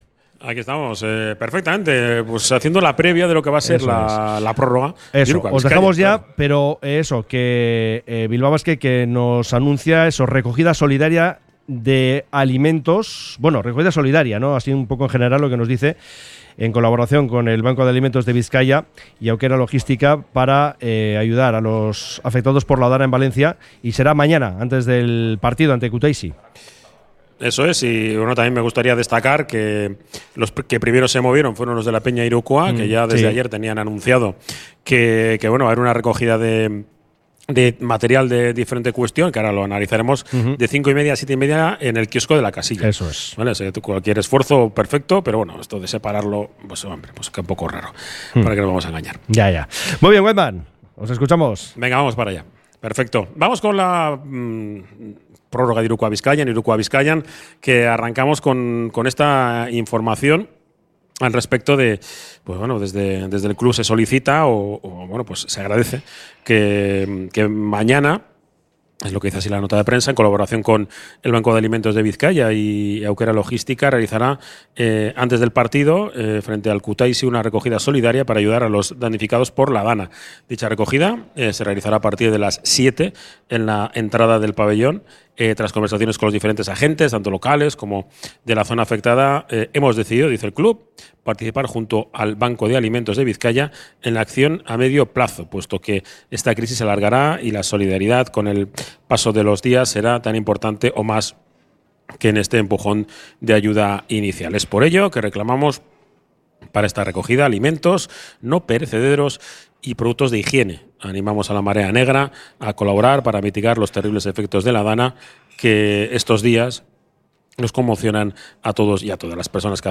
Desde el Bar Izar la Quinta Estrella de Santutxu